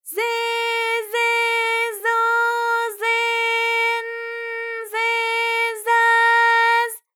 ALYS-DB-001-JPN - First Japanese UTAU vocal library of ALYS.